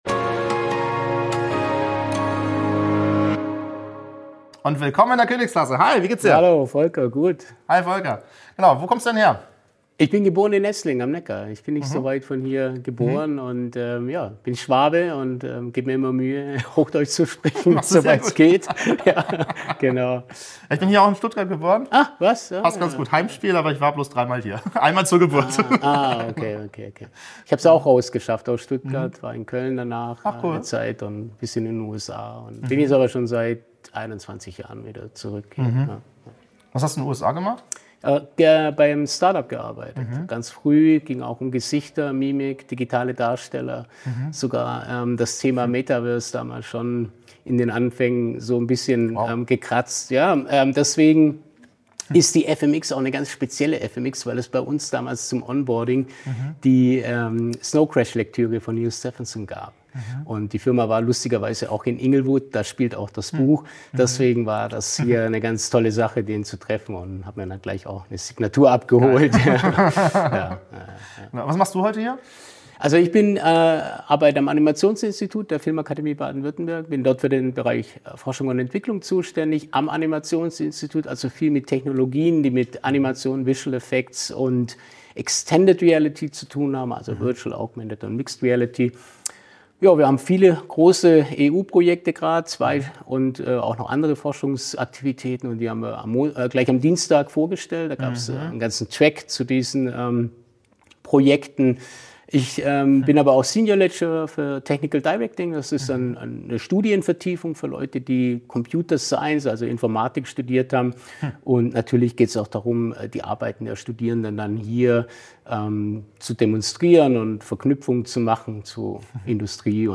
Zusammenfassung Das Gespräch findet auf der FMX-Konferenz in Stuttgart statt.